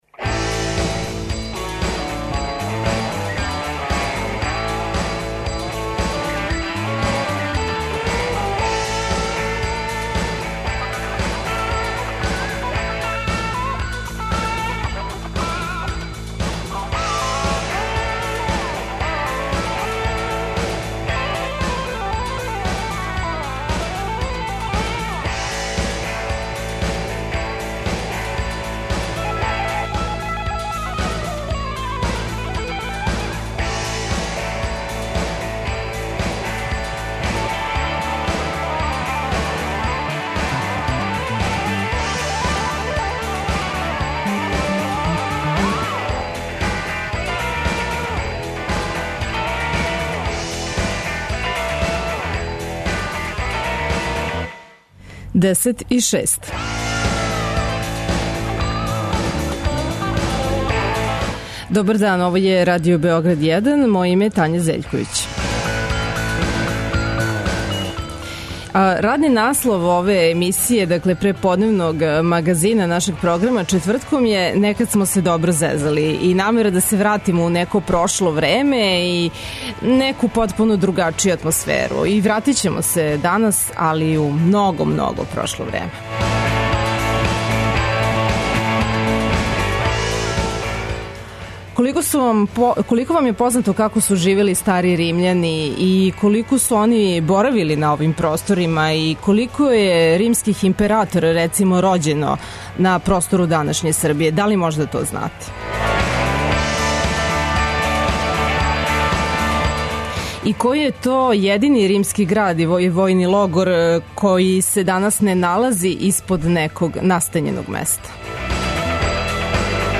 овога четвртка бира музику за вас. Која музика је обележила његово одрастање, а која обележава данашње дане? Причаћемо и о Виминациуму, манифестацији 'Римска ноћ', о наступу француске оперске диве Еме Шаплен, као и о новим налазиштима на овој локацији.